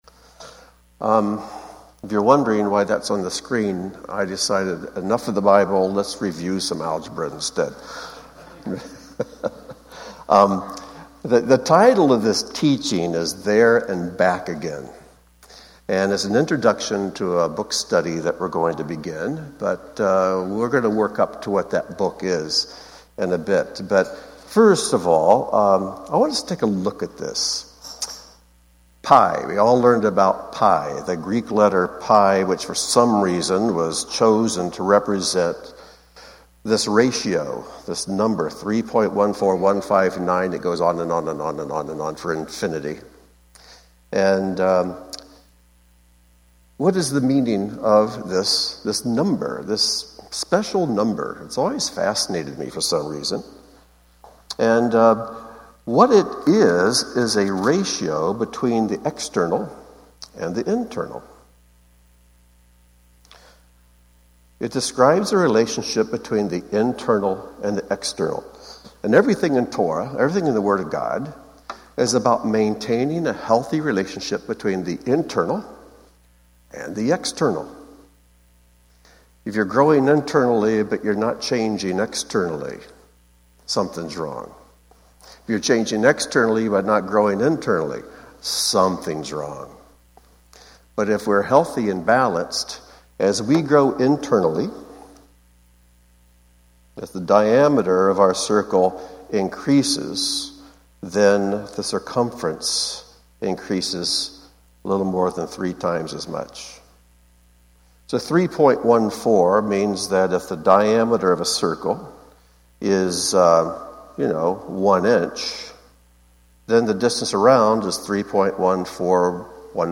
This introductory teaching to a new series on Ezra and Nehemiah is subtitled “There and Back Again,” referring to the purifying journey described in these books and which we also experience. Beginning with a connection to the novel “The Life of Pi,” we move on to a brief bird’s-eye view of Israel’s journey partly described in Ezra and Nehemiah: warning to give the land its Sabbath rests, consequences for not listening, awakening to the sin, deliverance, and return.